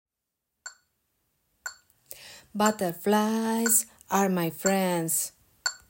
Rhythm and words
They contain phrases pronounced imitating the scores on the second column.